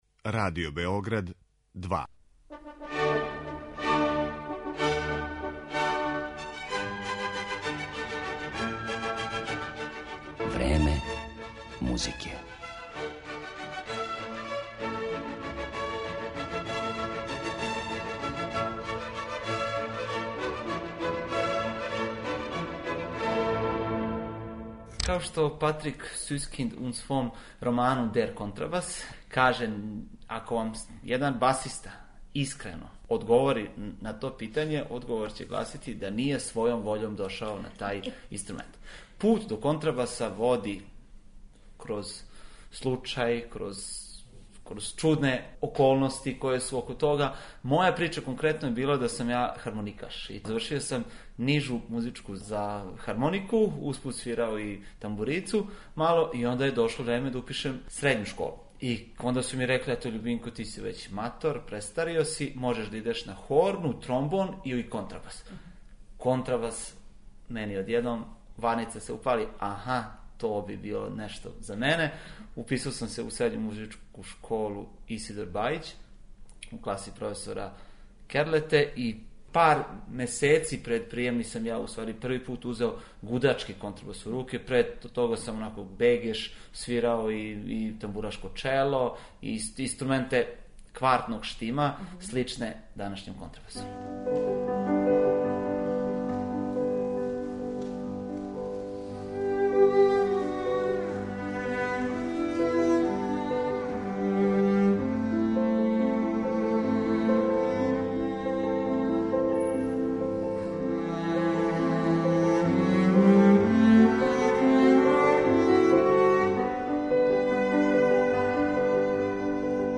док ћете у емисији моћи да чујете и музику Росинија, Мишека, Сен Санса, Баха, Листа и других.